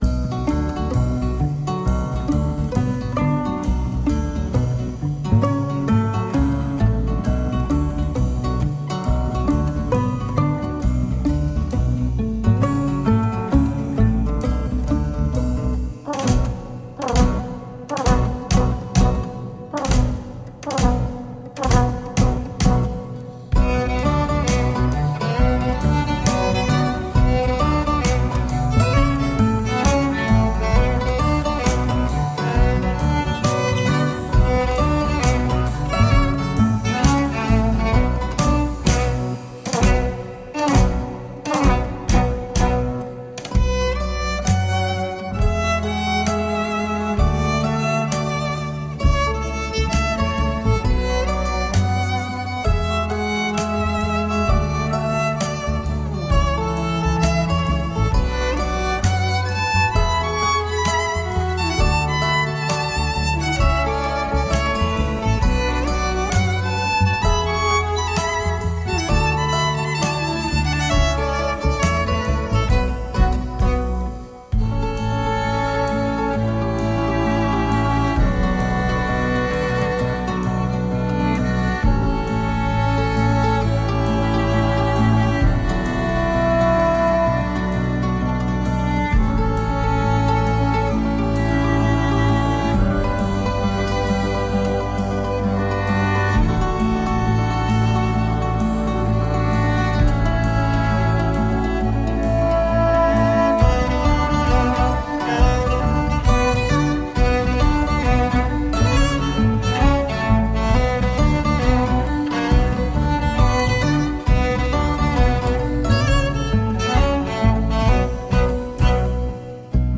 Early music meets global folk at the penguin café.
Tagged as: World, Folk-Rock